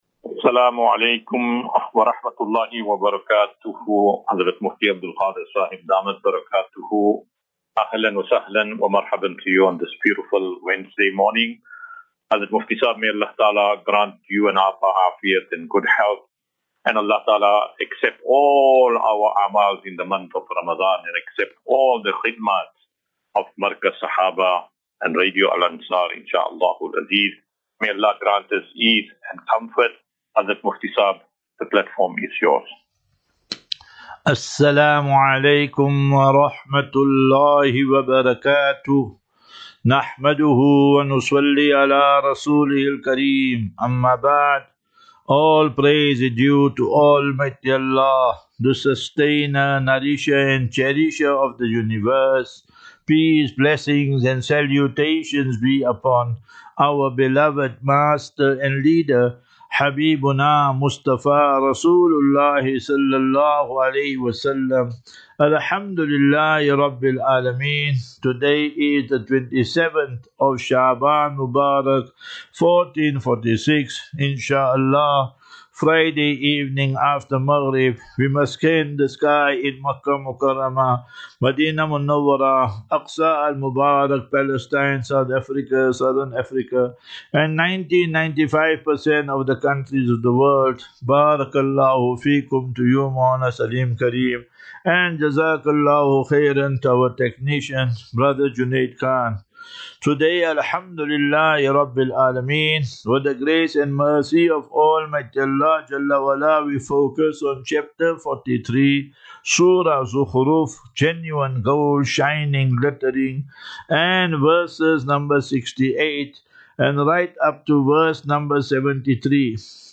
26 Feb 26 February 2025, Assafinatu - Illal - Jannah. QnA